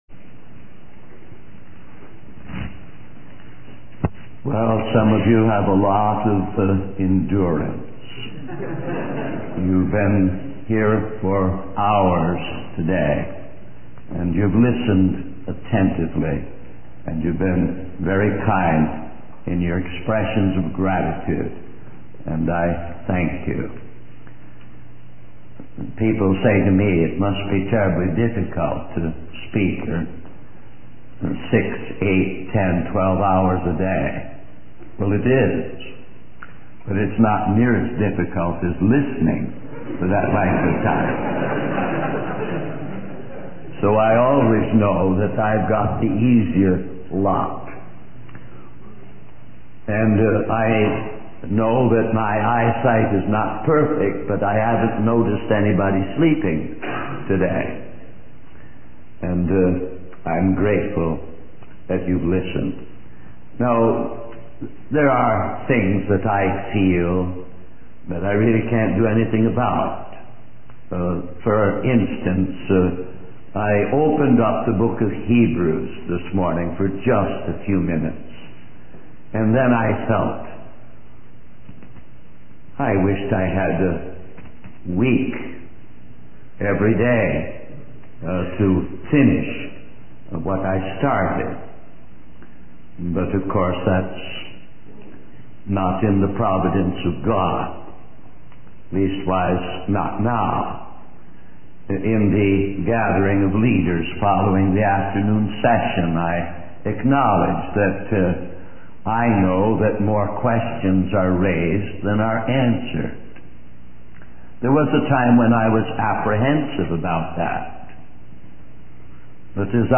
This sermon emphasizes the importance of seeking and surrendering to the Lord, highlighting the need for continuous seeking of God's face, repentance, and faith. It warns against complacency and compromise, urging believers to enter into a covenant to seek the Lord with all their heart and soul. The story of King Asa serves as a cautionary tale of starting well but failing to continue seeking God, leading to consequences and missed opportunities for restoration.